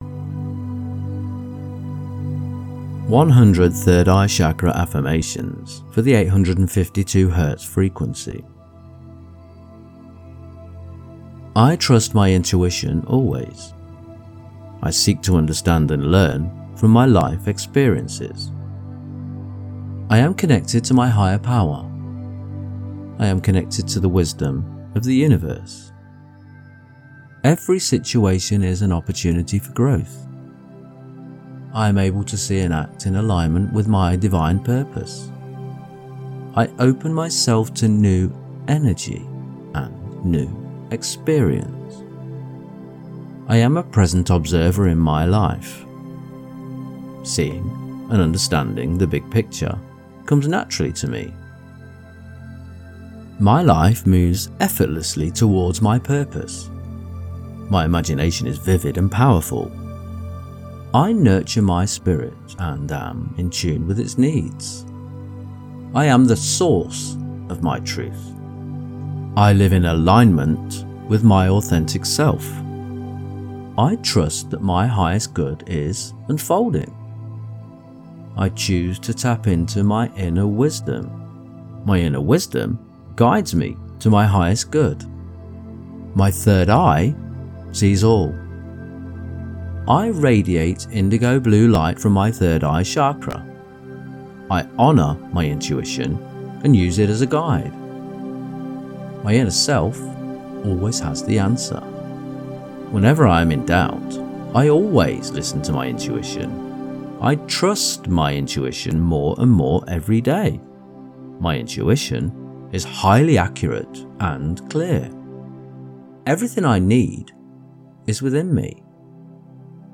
Welcome to our collection of 100 enlightening affirmations tuned to the high-frequency vibrations of 852 Hz. This Solfeggio frequency is associated with awakening spiritual awareness, expanding intuition, and connecting to your higher self. 852 Hz helps you dissolve mental distractions, align with your true purpose, and raise your vibration to experience heightened consciousness and inner peace.